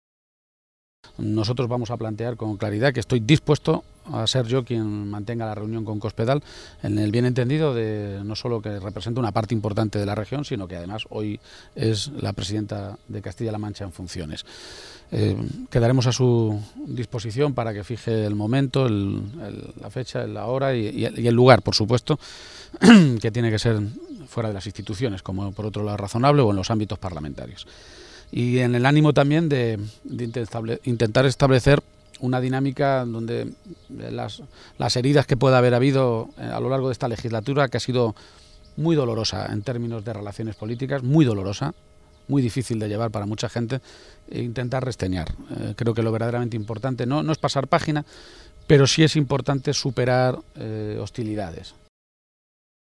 García-Page se pronunciaba de esta manera esta mañana, en Toledo, en una comparecencia ante los medios de comunicación minutos antes de que comenzara esa conversación con responsables de IU a nivel regional, en las Cortes de Castilla-La Mancha.